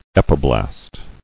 [ep·i·blast]